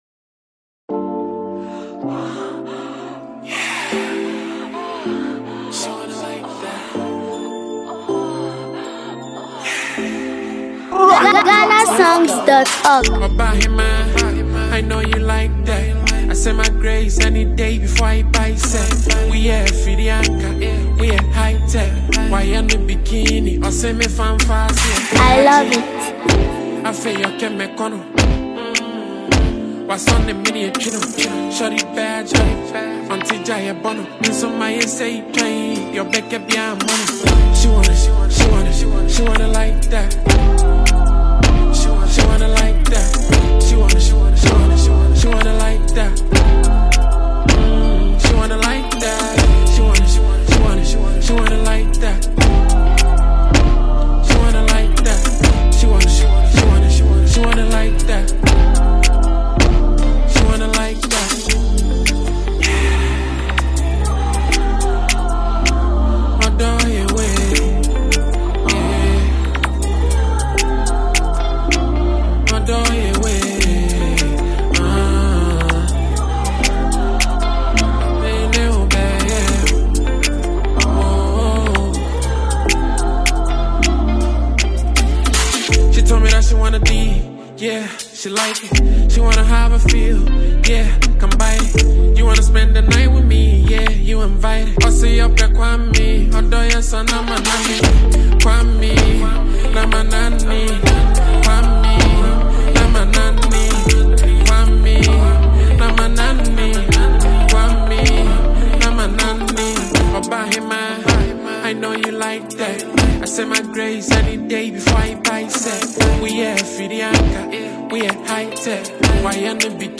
another infectious banger